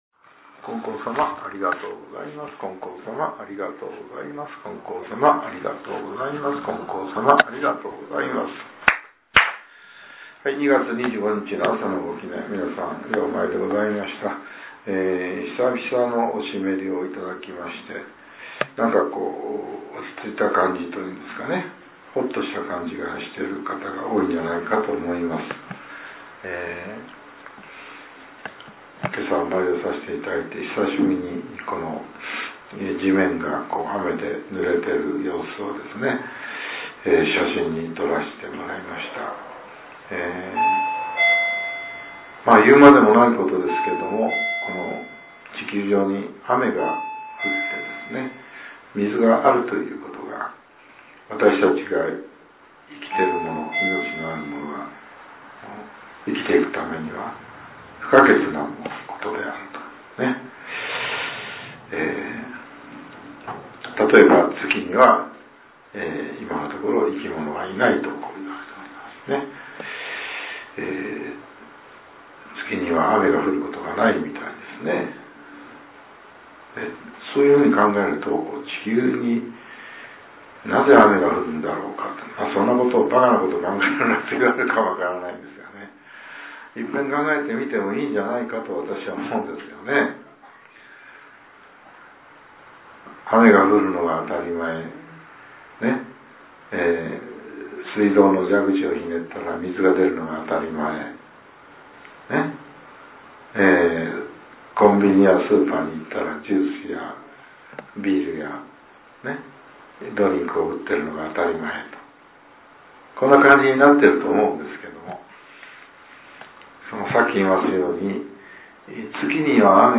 令和８年２月２５日（朝）のお話が、音声ブログとして更新させれています。